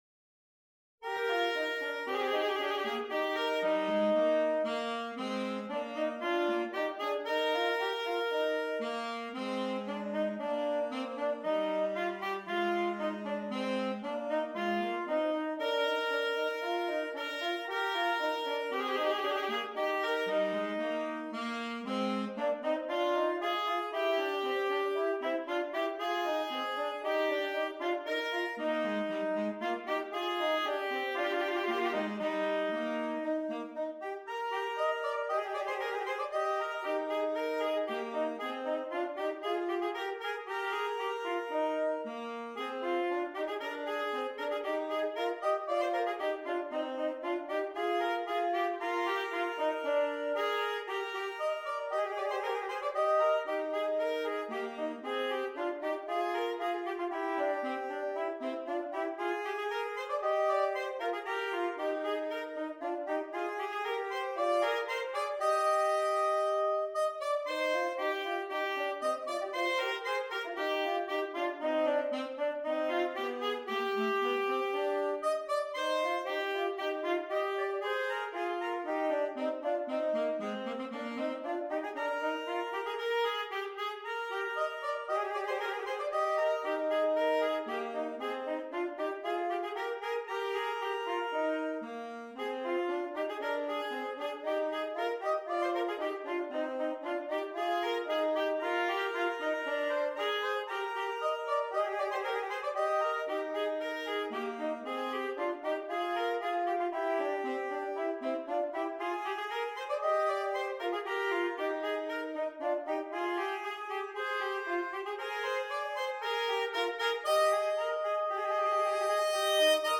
2 Alto Saxophones
versatile duet